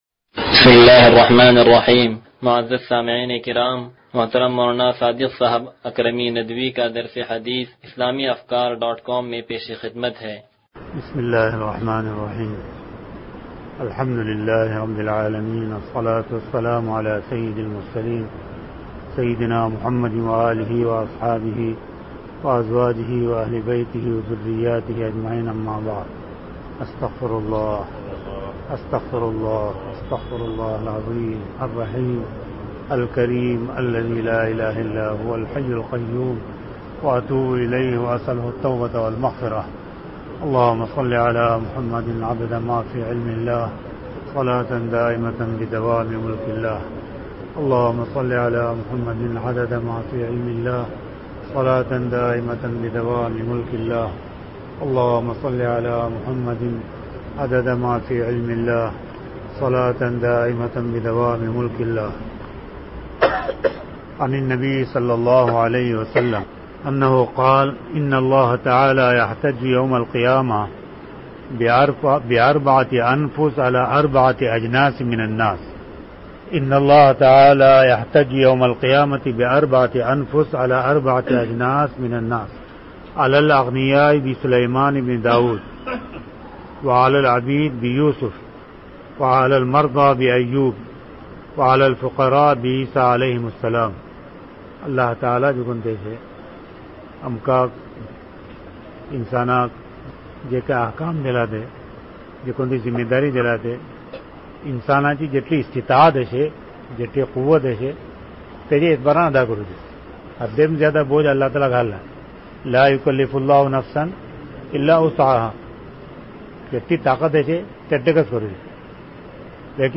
درس حدیث نمبر 0086